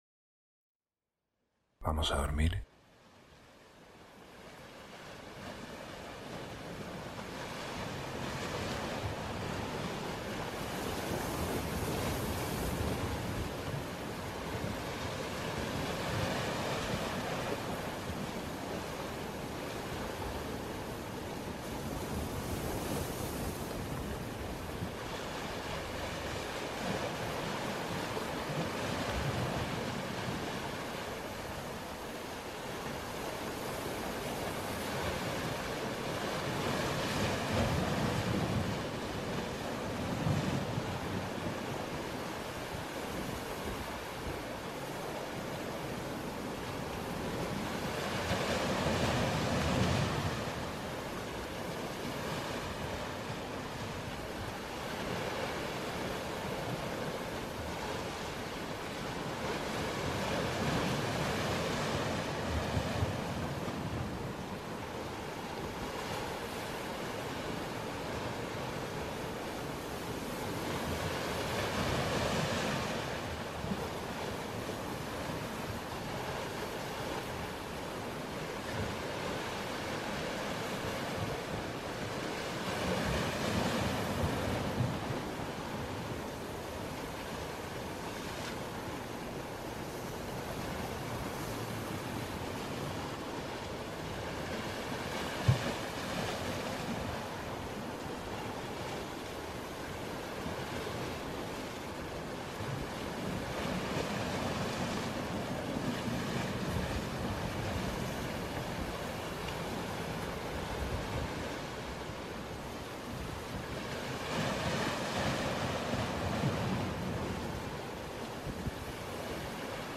Ambientes para Dormir ✨ Atardecer en la playa
Experiencias inmersivas para ayudarte a dormir profundamente.